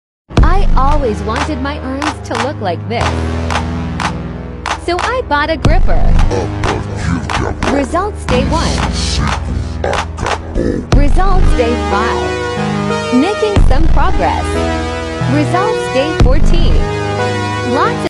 Silicone Gripster Hand Grip Trainer sound effects free download